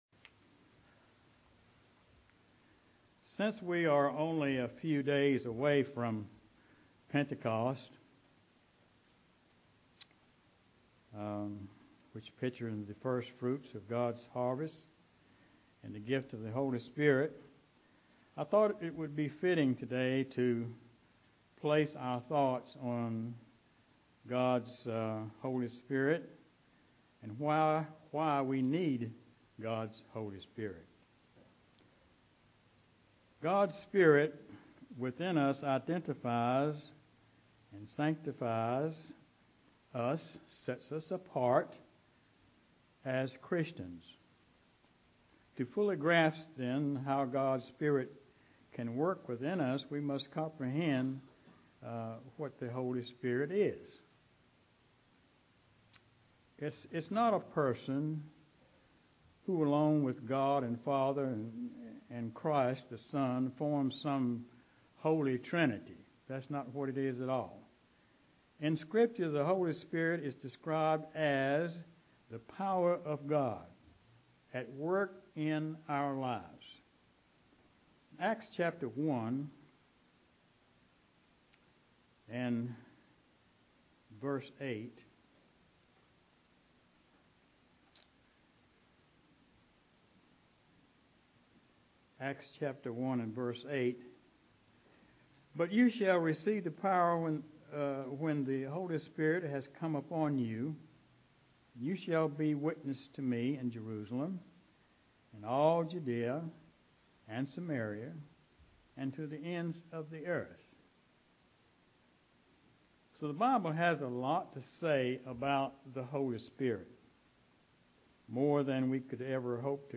Sermons
Given in Greensboro, NC